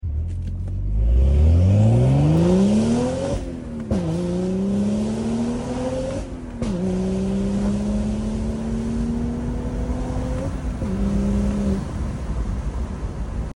Nothing Beats Those 6MT Shift Sound Effects Free Download